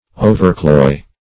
Overcloy \O`ver*cloy"\, v. t. To fill beyond satiety.